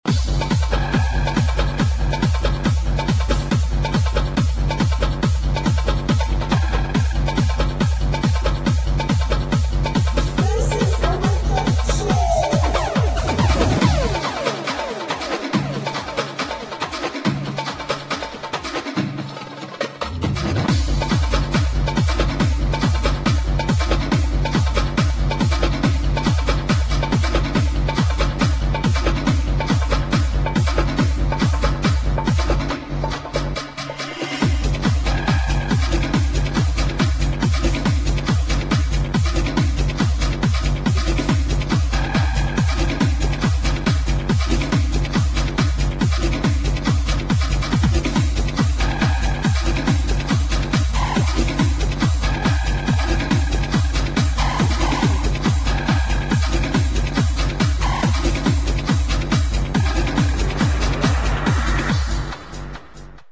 [ HARD HOUSE / TECHNO ]